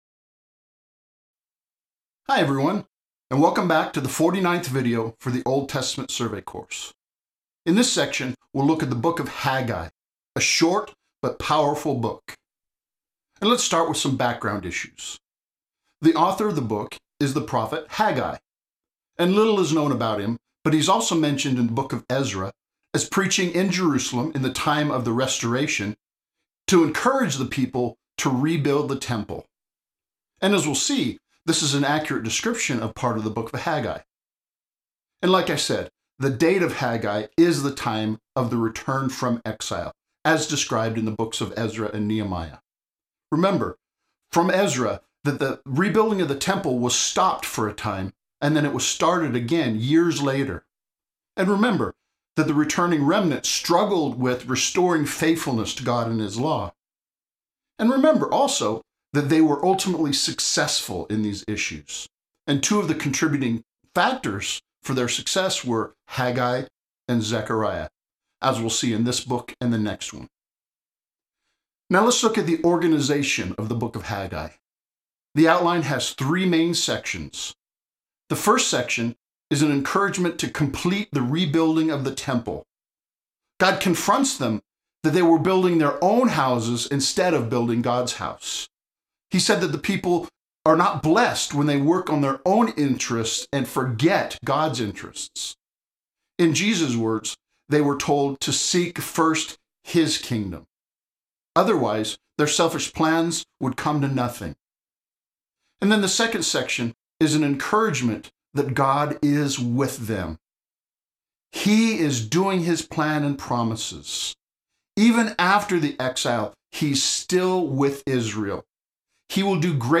The second section has a survey video lecture covering the entire book.